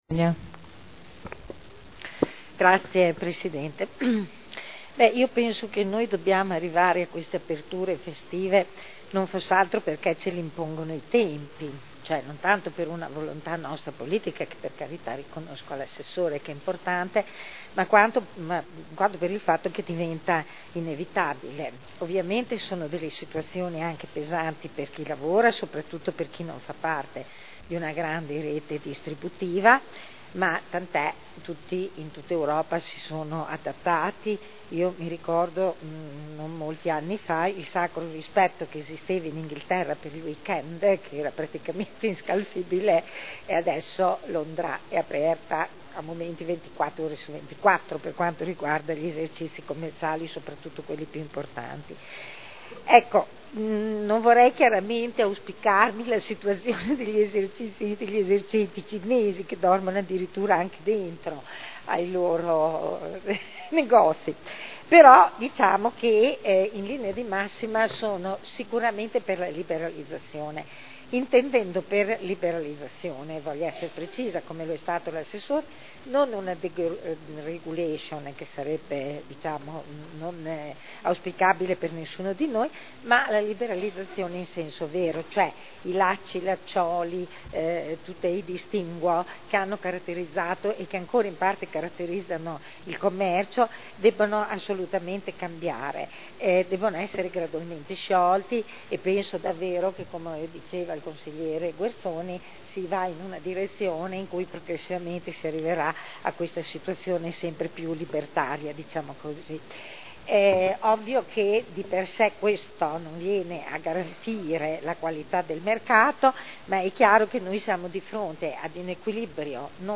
Seduta del 05/12/2011. Dibattito.